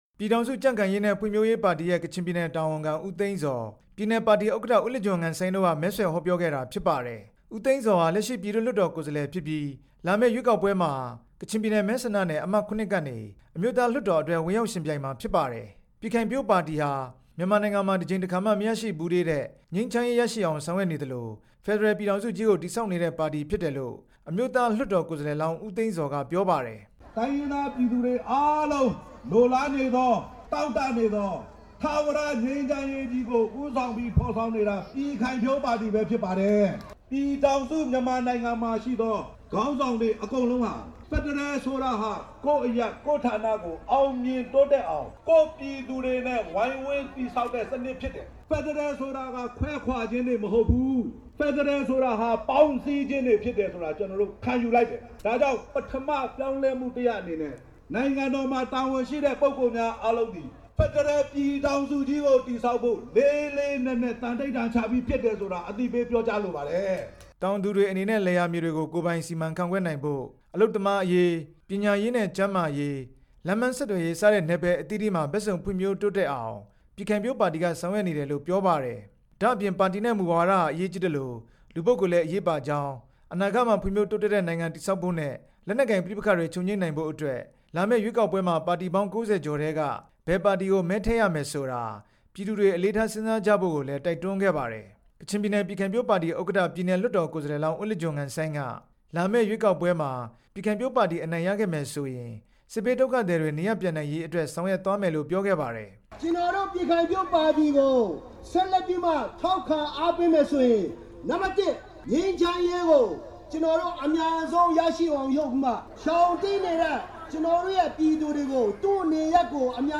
ကချင်ပြည်နယ် မဲဆန္ဒနယ်တွေမှာ ဝင်ရောက်ယှဉ်ပြိုင်ကြမယ့် ပြည်ခိုင်ဖြိုးပါတီ ကိုယ်စားလှယ်လောင်း ၂ဝ လောက်ဟာ မြစ်ကြီးနားမြို့ စီတာပူရပ်ကွက်က မနော အားကစားကွင်းမှာ ဒီနေ့ မဲဆွယ်စည်းရုံးဟောပြောခဲ့ပါတယ်။